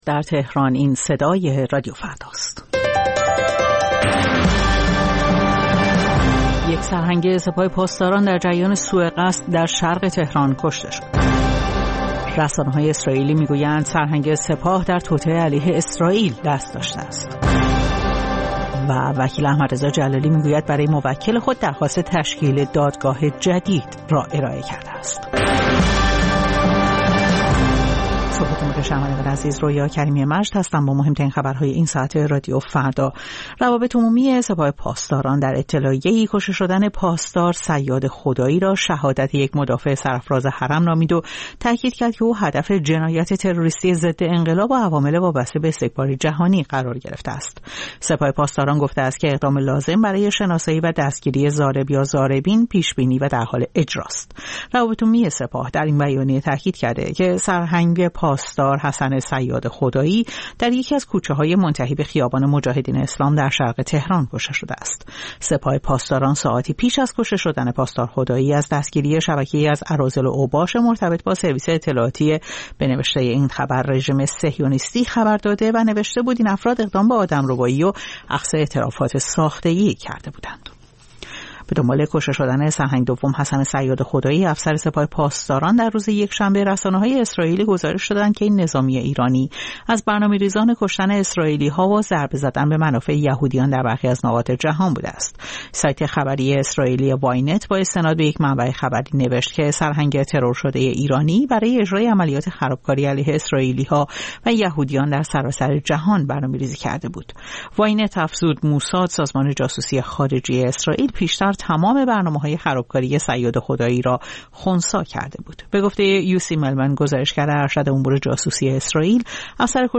سرخط خبرها ۲:۰۰